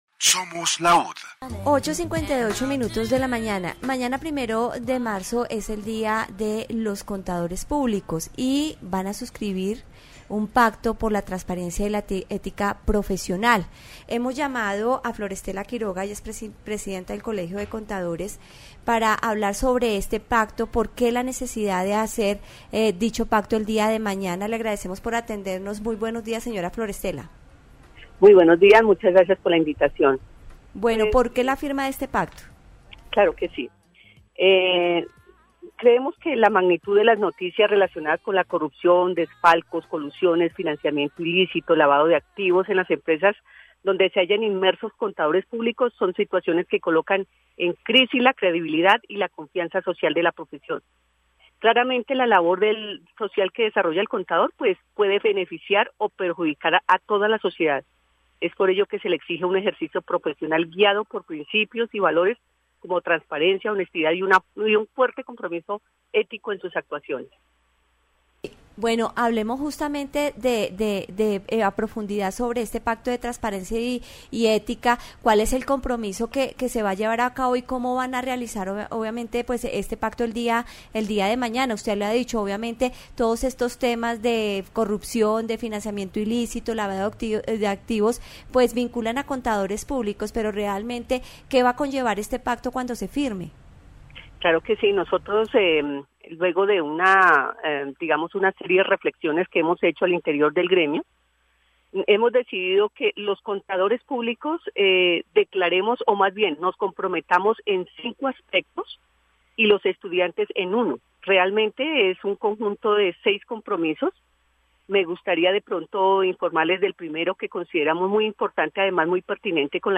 Universidad Distrital Francisco José de Caldas. Emisora LAUD 90.4 FM
Programas de Radio